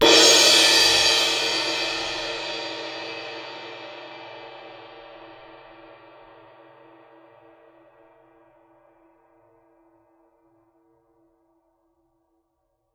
susCymb1-hit_fff_rr1.wav